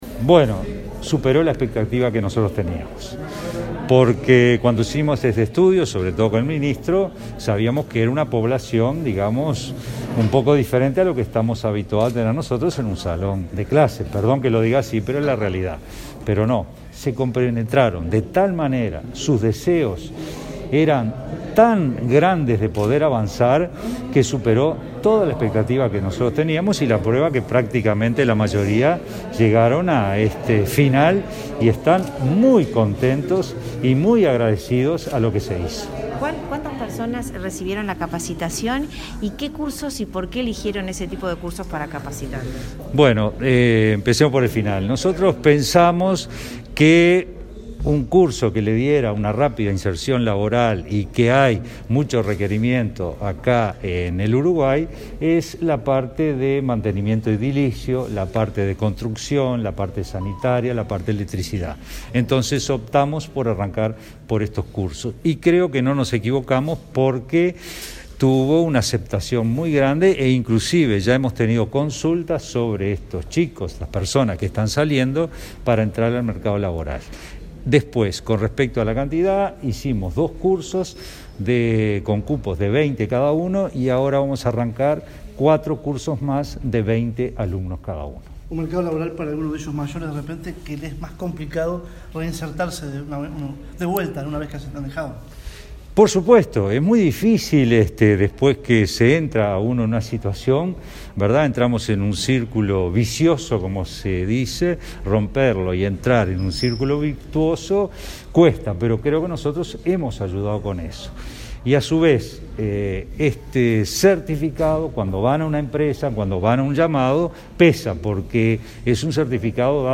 Declaraciones a la prensa del director de UTU, Juan Pereyra
Al finalizar el acto, Pereyra dialogó con la prensa.